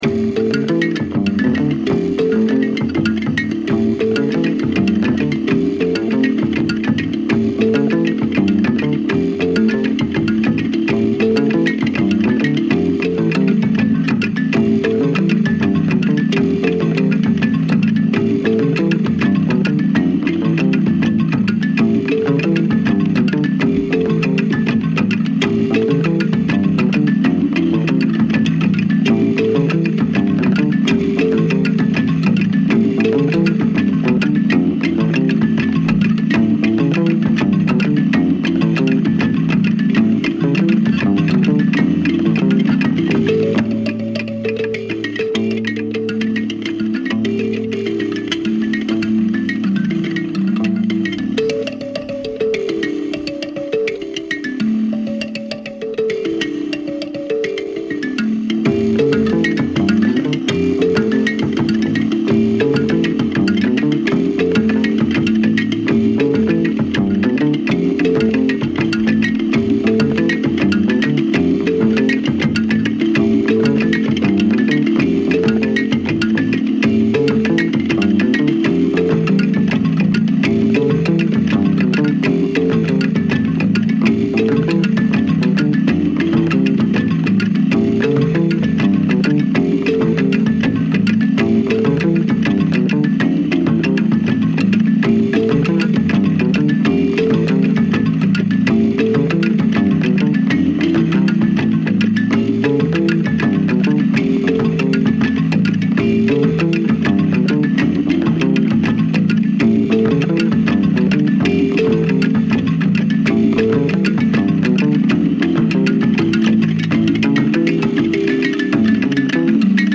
African finger piano